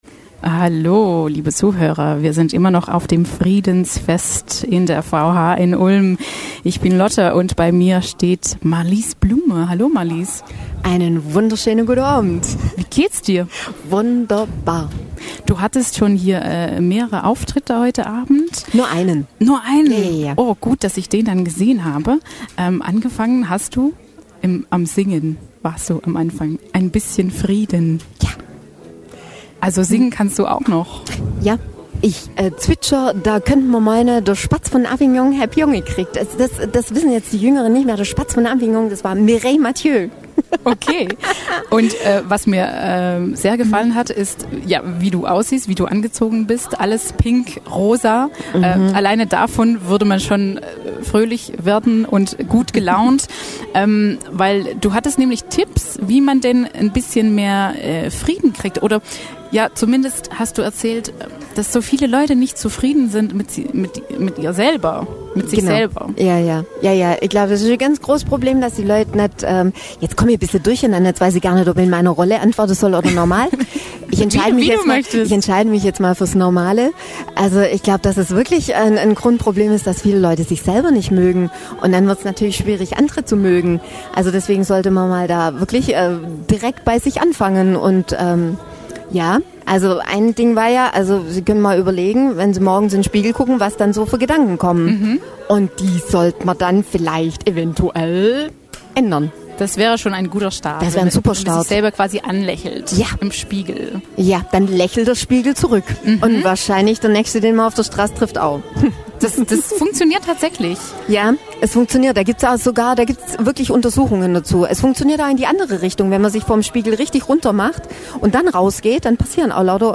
Zum Friedensfest haben wir uns mit einigen Teilnehmern der Ulmer Friedenswochen unterhalten.